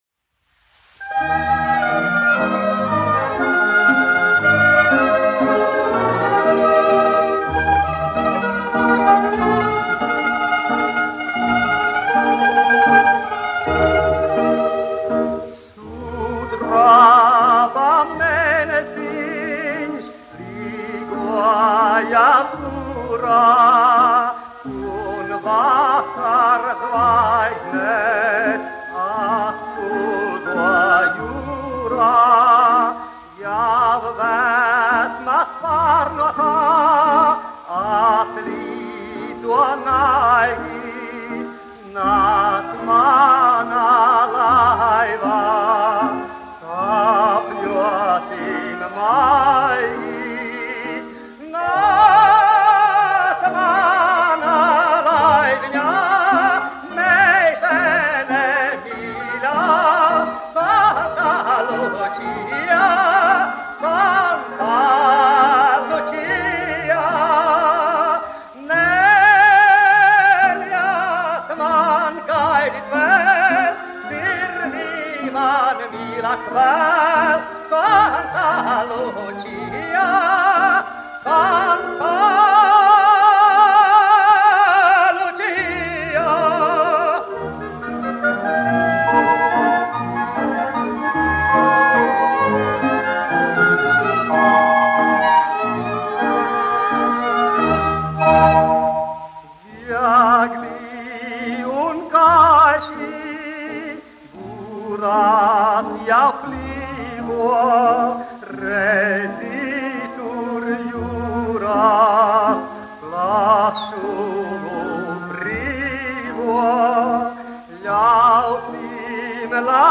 1 skpl. : analogs, 78 apgr/min, mono ; 25 cm
Populārā mūzika -- Itālija
Latvijas vēsturiskie šellaka skaņuplašu ieraksti (Kolekcija)